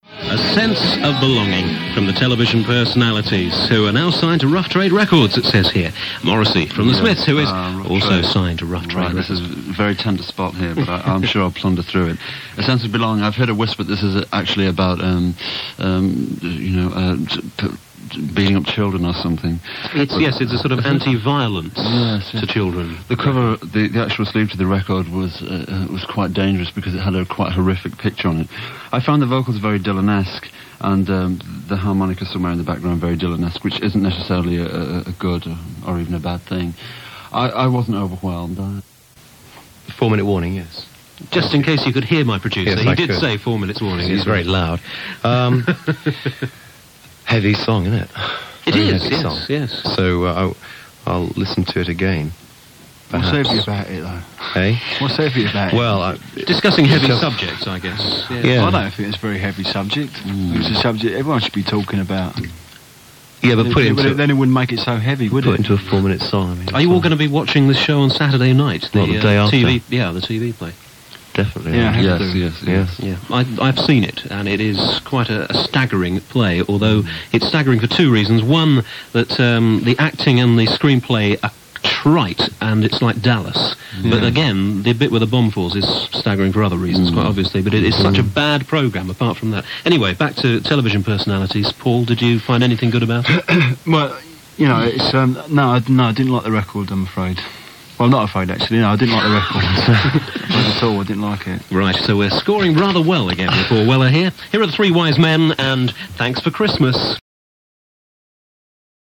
The new TVP compilation album -- 'And They All Lived Happily Ever After' (unreleased live and archive material) -- contains a brief snippet from Radio 1's 'Round Table' (1983) of Paul Weller and Morrissey discussing the single: 'A Sense of Belonging'.
television personalities - 10 - paul weller and morrissey review 'a sense of belonging'.mp3